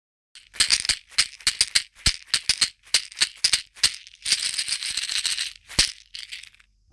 ストローがらがら 小
水草を編み上げた小さなカゴの中に、響きのよい小石や種が入っています。丸くカットしたひょうたんが底部分に組み込まれ、ジャカジャカ?♪と優しく心地よい音、自然の音が和みます。南米ででは「カシシ」とも呼ばれているシェーカー。
素材： 水草 ヒョウタン 種 小石